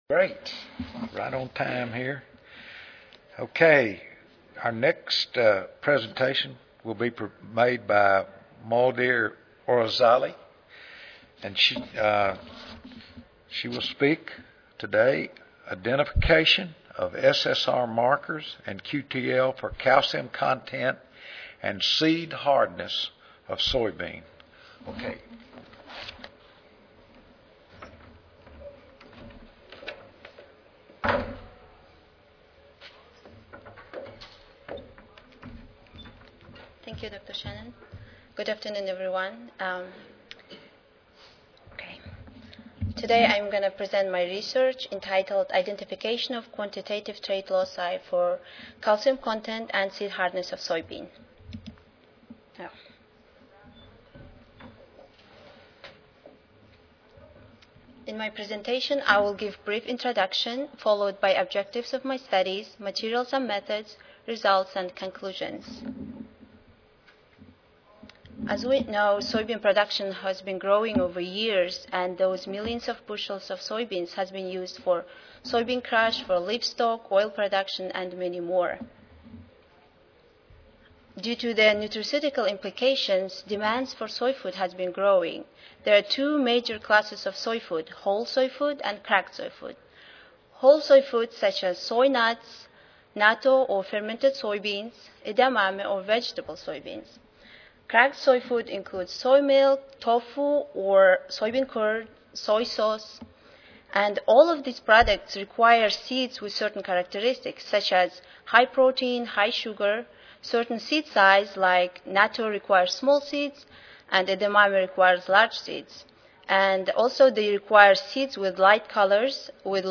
C01 Crop Breeding & Genetics Session: Crop Breeding and Genetics: Soybean and Oilseed Crops (ASA, CSSA and SSSA Annual Meetings (San Antonio, TX - Oct. 16-19, 2011))
Virginia State University Recorded Presentation Audio File